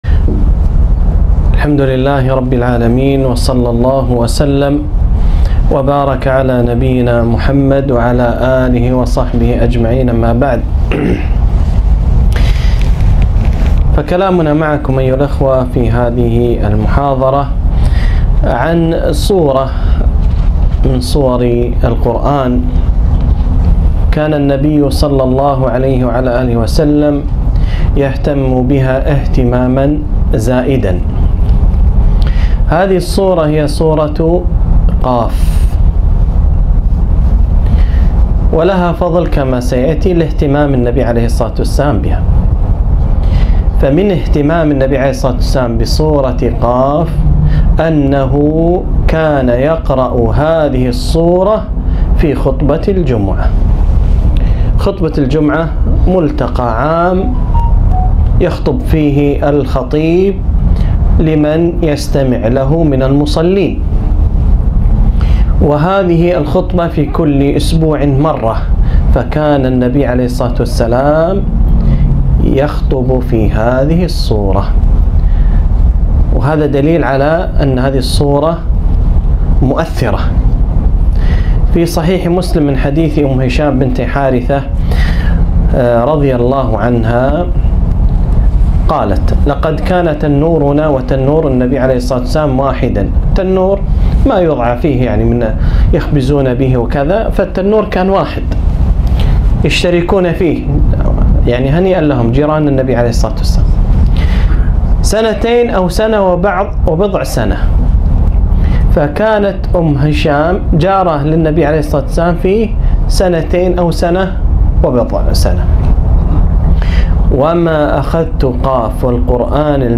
محاضرة - تأملات قرآنية من سورة ق